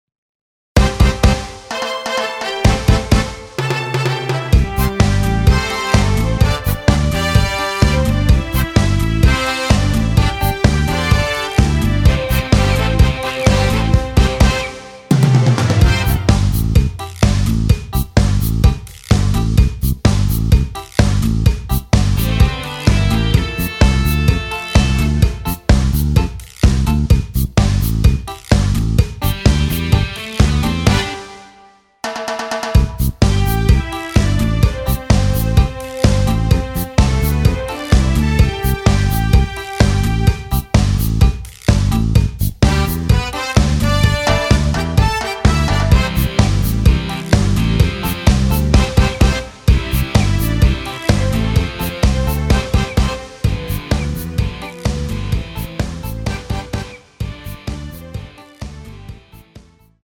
원키에서 (-2)내린 MR 입니다.
앞부분30초, 뒷부분30초씩 편집해서 올려 드리고 있습니다.
중간에 음이 끈어지고 다시 나오는 이유는
곡명 옆 (-1)은 반음 내림, (+1)은 반음 올림 입니다.